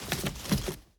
Bow Put Away 1.wav